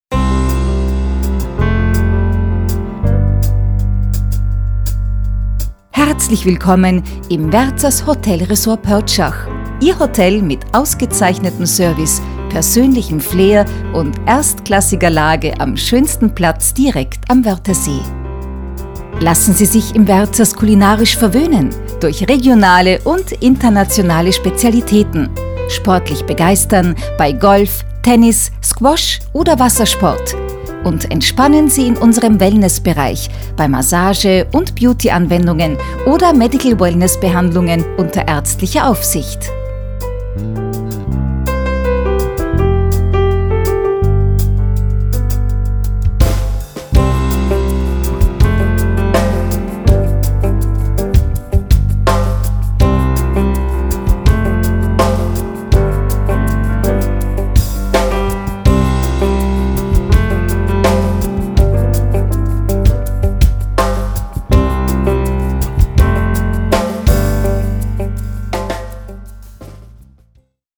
Sprechprobe: Industrie (Muttersprache):
Austrian voice over artist with professional studio. My voice is smooth, warm, friendly, solid, confident and trustable.